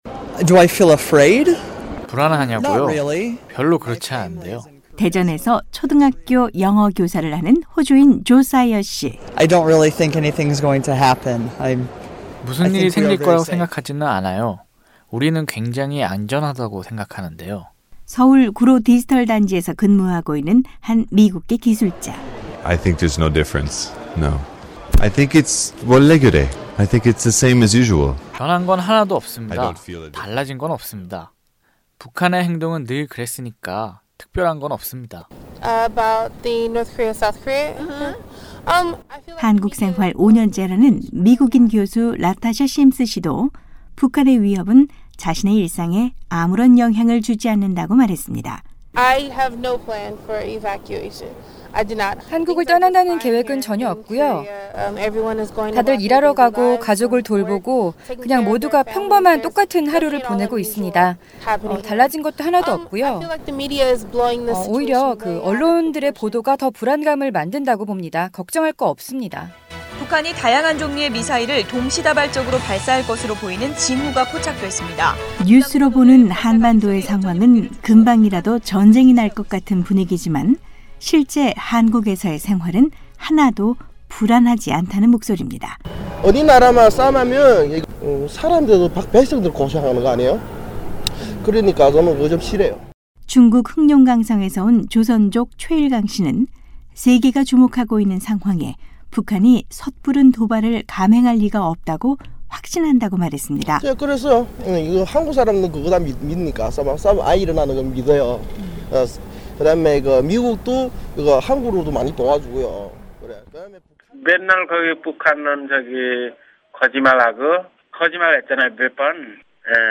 서울입니다’ 오늘은 북한의 위협에도 평범한 일상을 유지하고 있는 한국에 사는 탈북자들과 외국인들의 목소리를 들어보겠습니다. 외국인들의 안전 보장을 위한 대피계획이 필요할 것이라는 북한의 위협(9일)에도 한국에 사는 외국인들은 전혀 동요하지 않았고, 탈북자들은 혼란을 조성하려는 북한의 전략에 불안해할 필요가 없다고 말했습니다.